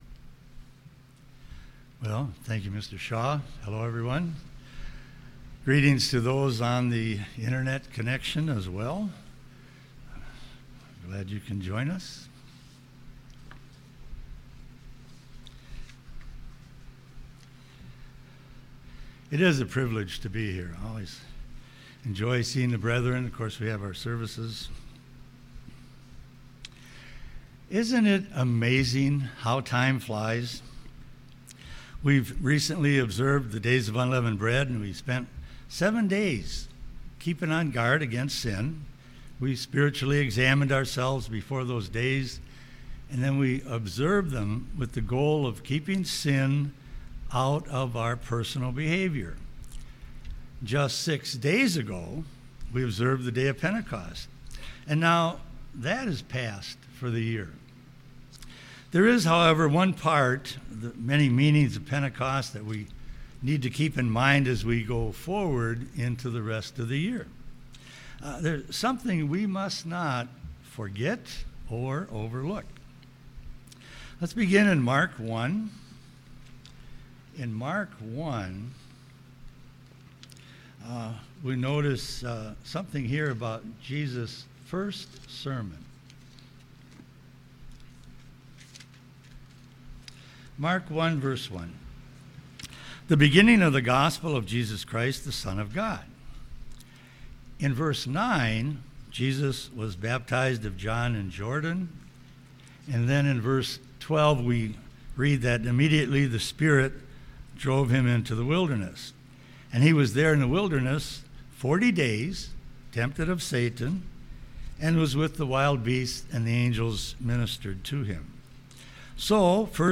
Sermon
Given in Twin Cities, MN